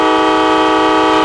5 chime horn 3a.ogg